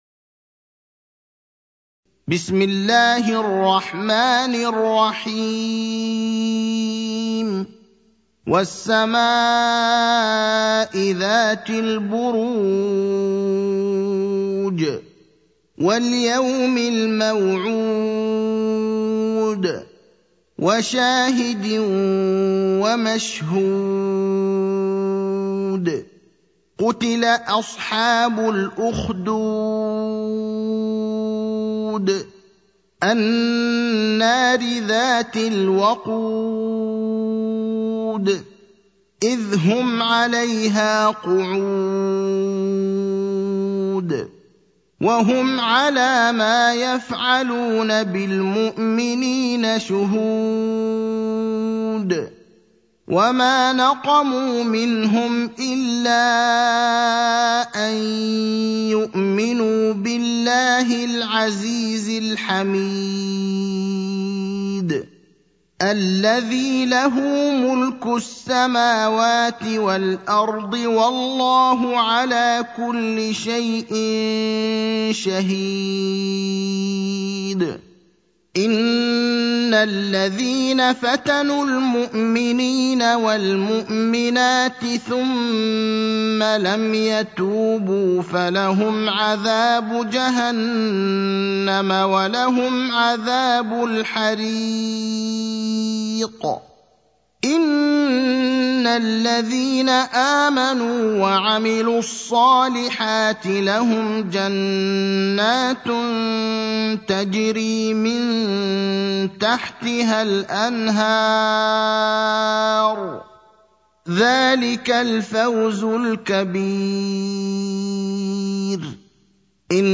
المكان: المسجد النبوي الشيخ: فضيلة الشيخ إبراهيم الأخضر فضيلة الشيخ إبراهيم الأخضر البروج (85)